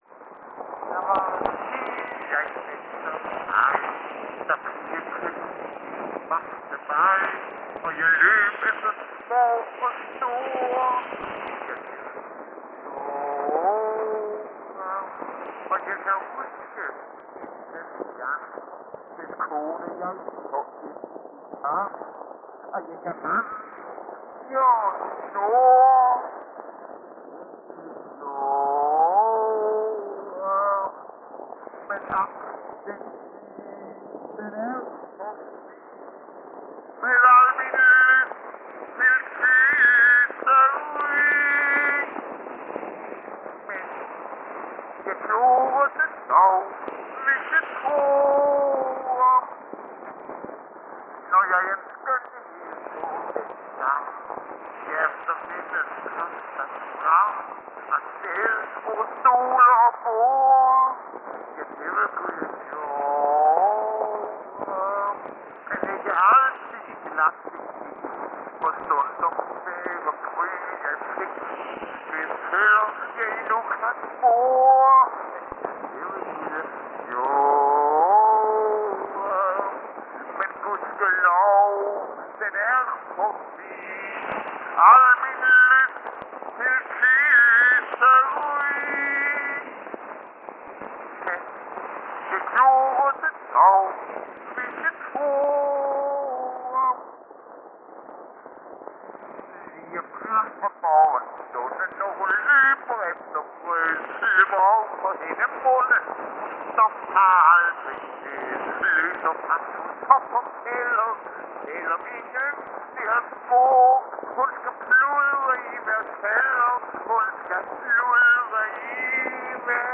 Første sang er komplet (2 vers), mens anden sang, sunget på tysk melodi, udgøres af vers 1 og 3.